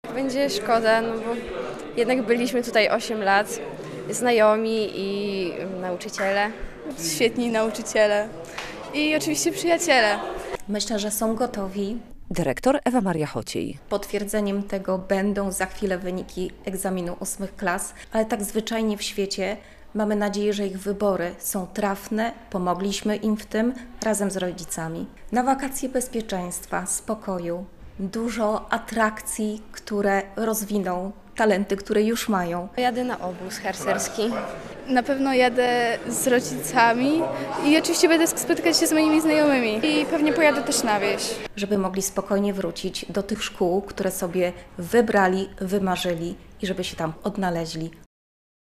Ósmoklasiści z podstawówki nr 50 w Białymstoku po wakacjach rozpoczną naukę w innych szkołach - relacja